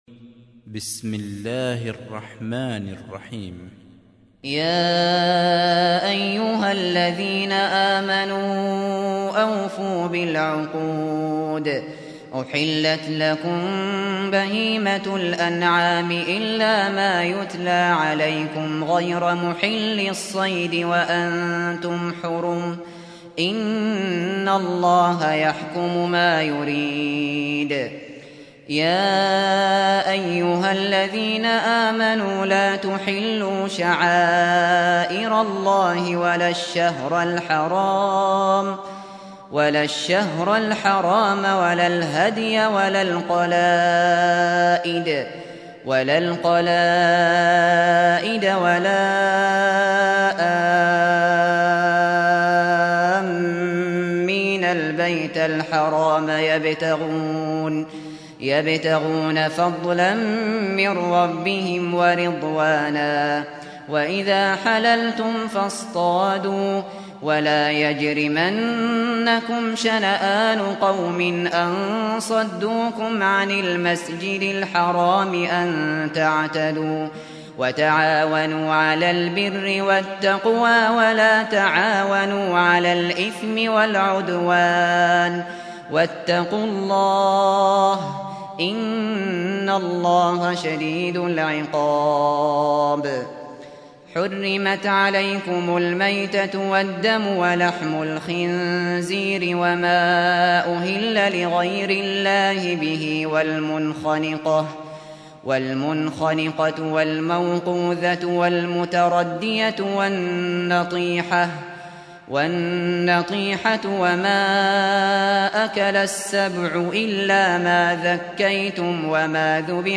سُورَةُ المَائـِدَةِ بصوت الشيخ ابو بكر الشاطري